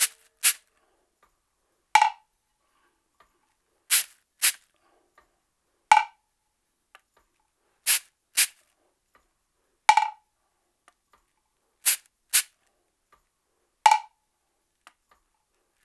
121-PERC2.wav